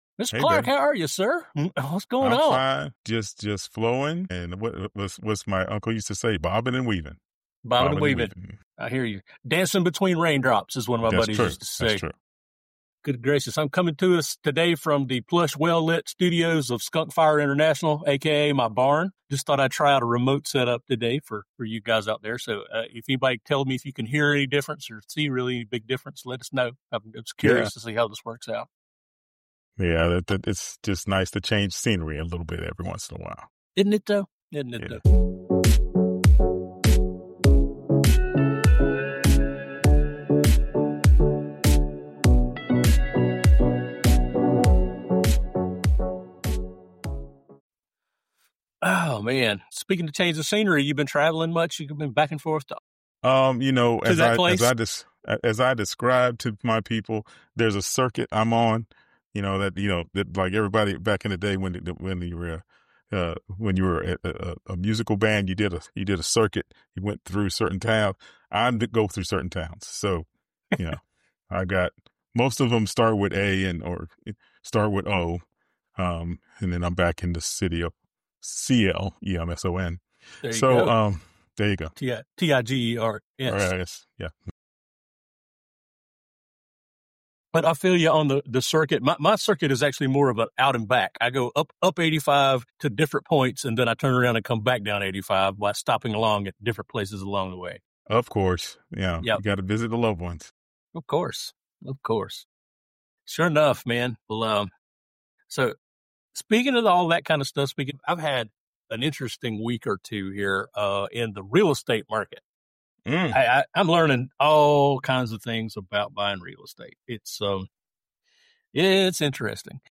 "Care Tech & Tips" is like tech support meets family therapy, with two guys who’ve been there. The podcast that helps you bring order to caregiving chaos. Get smarter with tech, money, and care strategies—without the overwhelm.